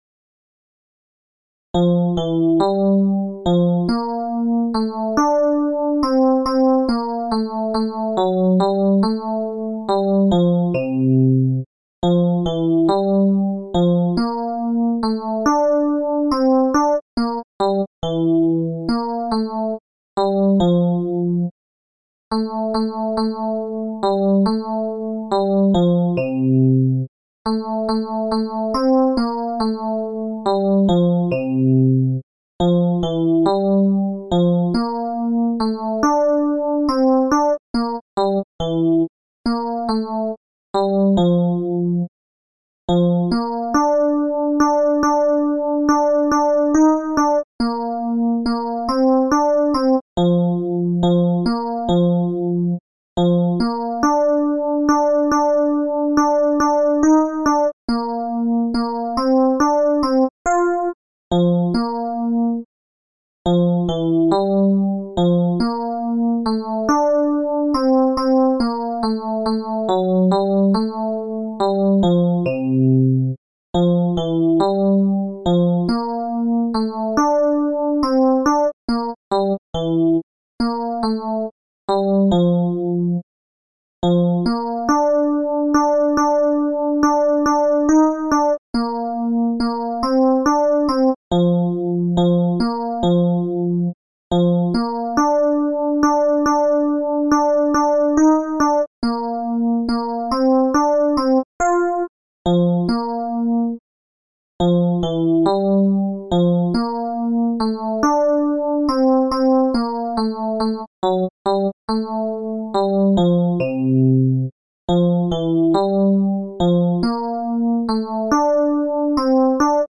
Ténors
barcarola_tenors.MP3